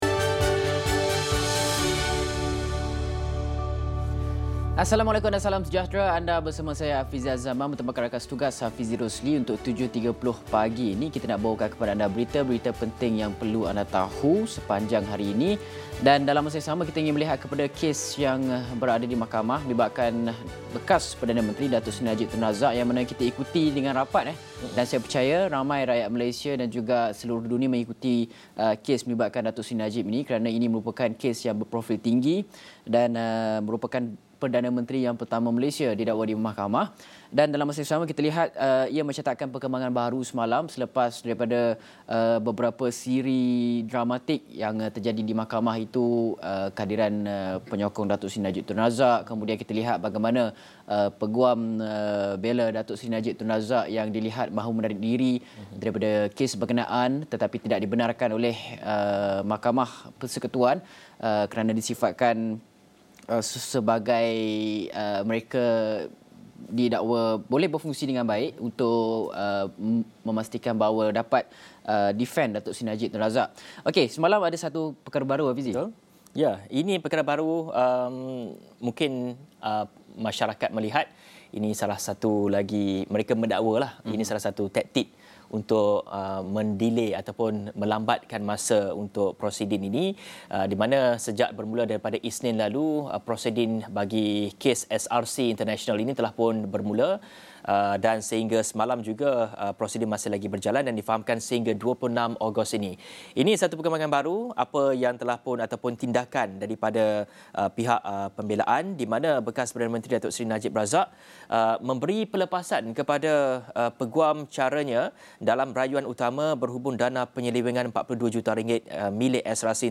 [LANGSUNG] AWANI Pagi: Berita tumpuan & kemas kini COVID-19 [20 Ogos 2022]